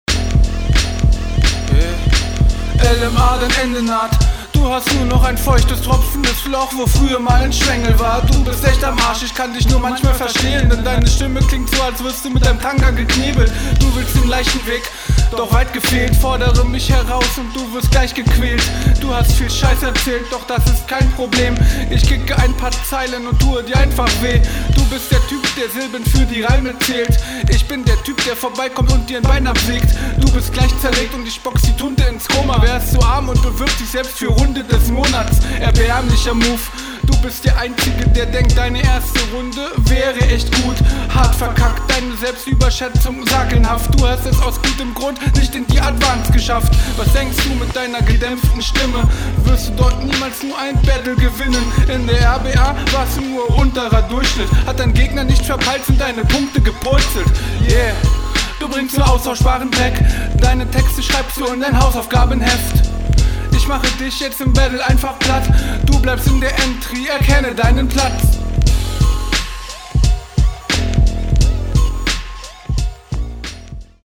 Okay auf dem Beat kommst du richtig gut rein, die Betonung passt echt gut.